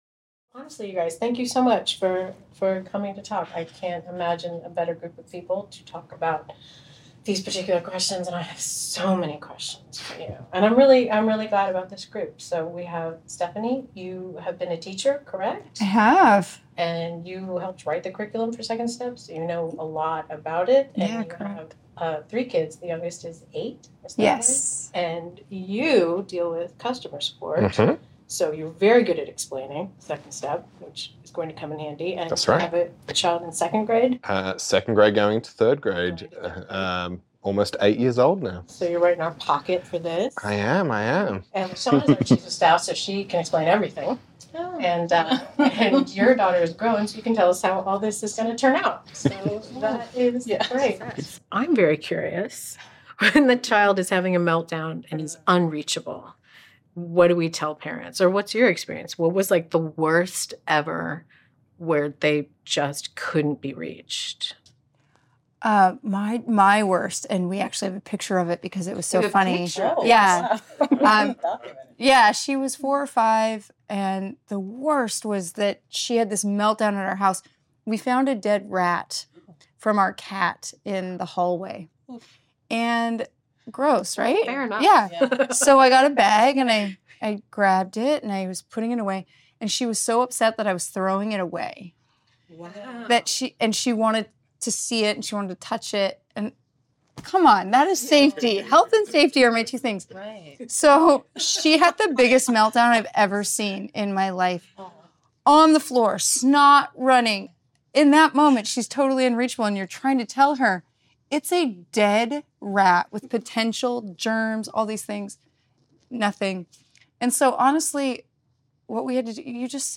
In this upbeat, podcast-style video chat, parents from the Second Step team share their own experiences building kids' emotion management skills. From tips for keeping cool when your child's big feelings are rubbing off on you, to the benefits of just monitoring their meltdowns, our parents share helpful insights into navigating this phase of their development with patience, grace, and confidence!